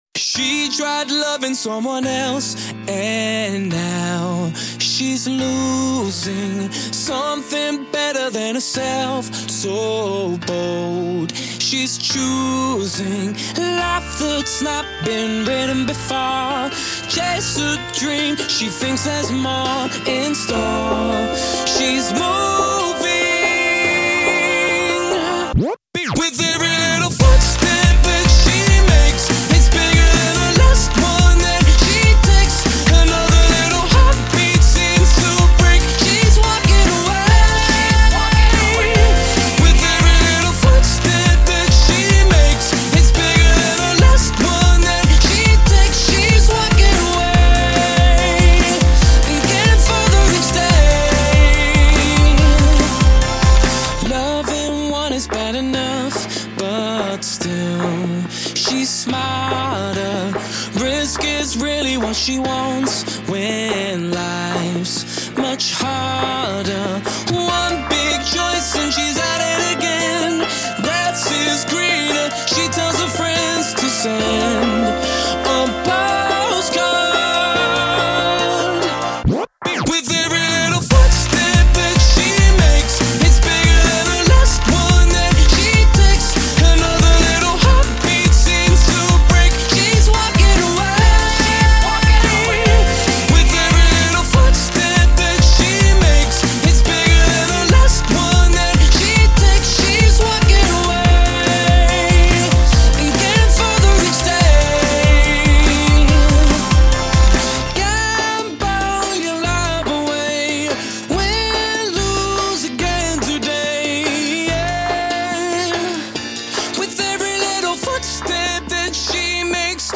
世界R&BDJ舞曲 激情巅峰跨越全球 迪厅王者至尊
炽热的音乐疯狂的节奏充满激情的活力